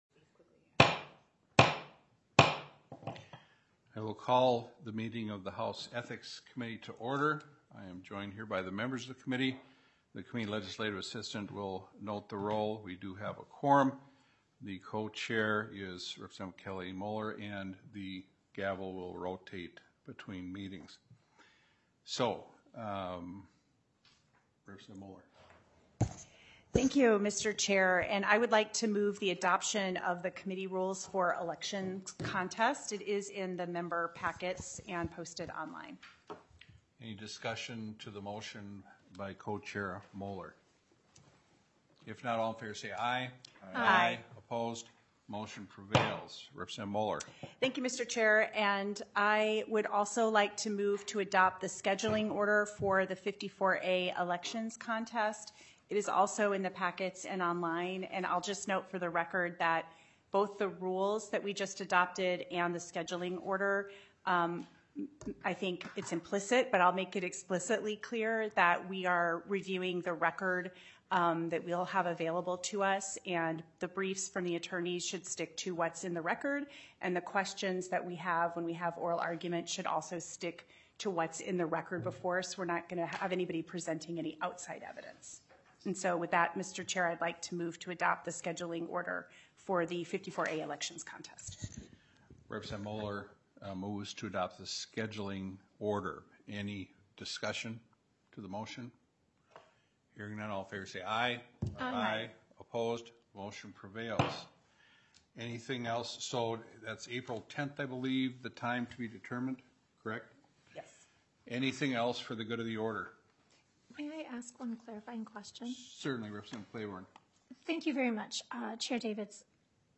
Representative Davids, Co-Chair of the Ethics Committee, called the meeting to order at 4:30 PM on March 27, 2025, in Room 120 of the State Capitol Building.